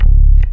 BASS 4 110-L.wav